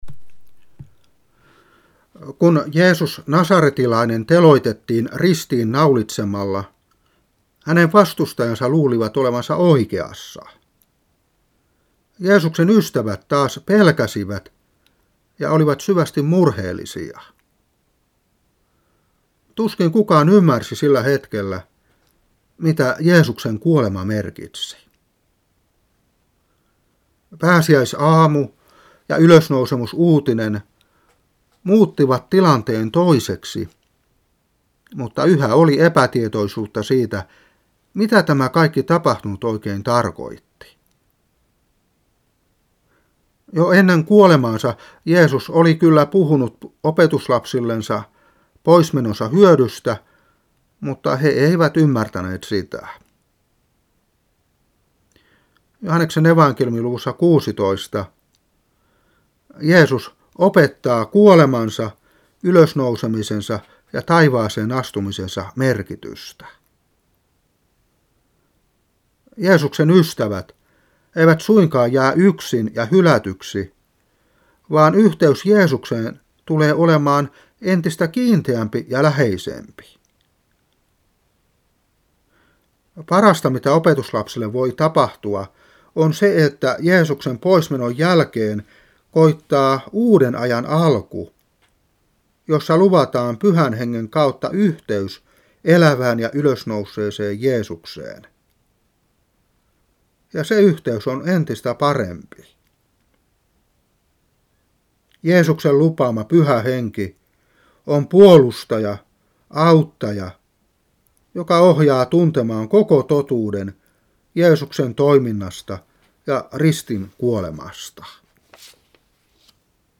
Saarna 1996-5.